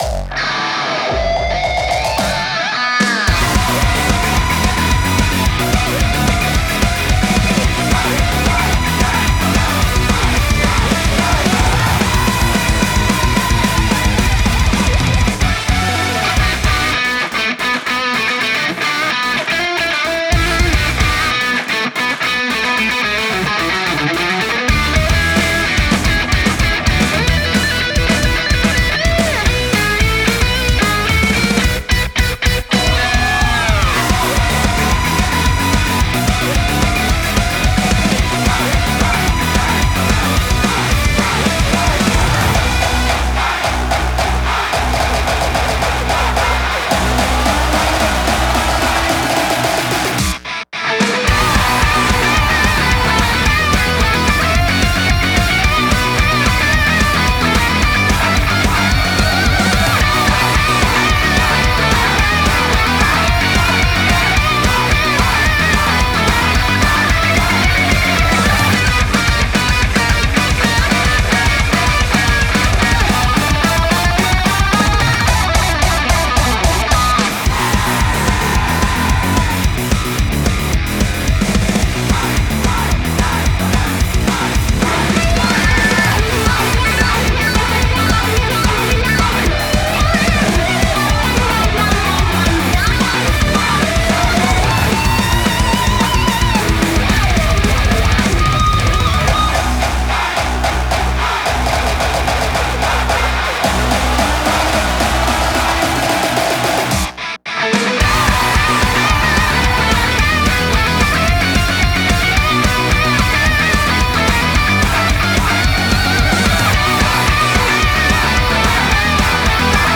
BPM220
Audio QualityPerfect (High Quality)
hardstyle kicks bwam bwam bwam